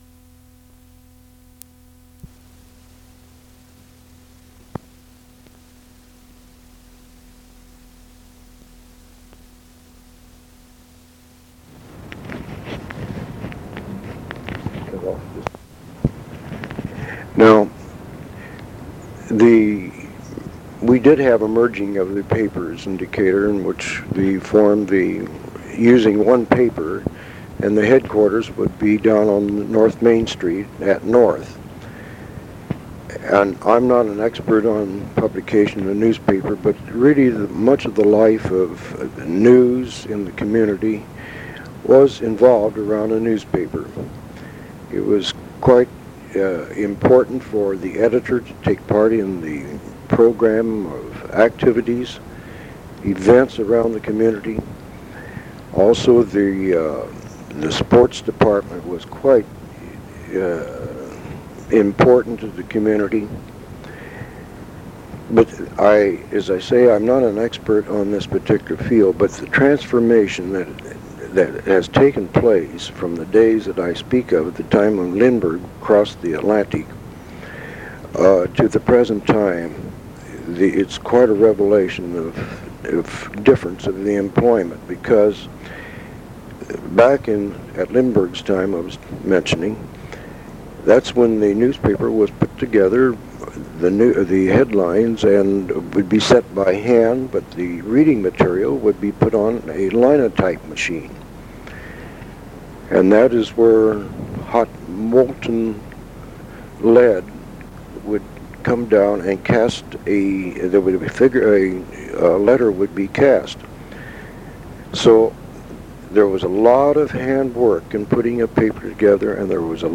interview
oral history